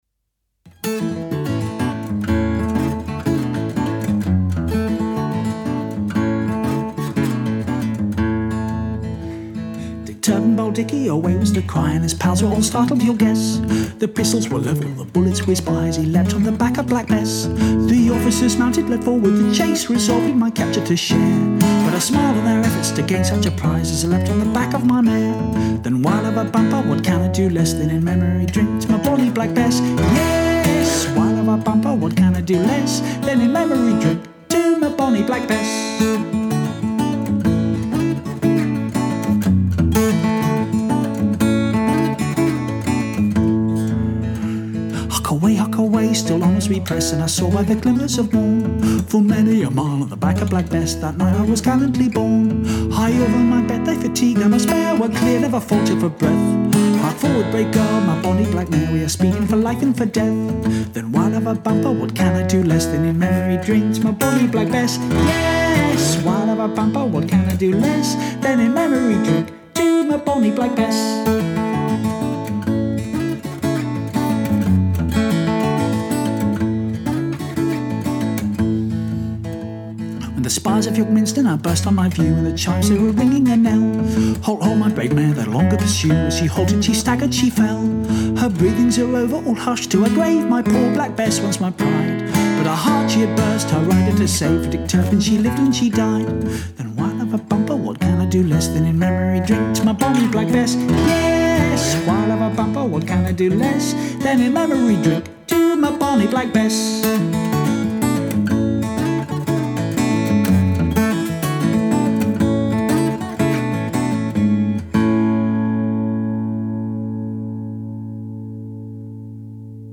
I recorded the songs live, just myself, guitar & a bunch of microphones.
This folk song most likely started as a music hall song in the mid 19th century before migrating into the tradition.
I found the song in the pages of Marrow Bones, the EFDSS collection of Hammond & Gardiner manuscripts described by Martin Carthy as “great raw material” & arranged it for guitar & voice as you hear. It’s quite a tricky song to play but I find it good fun!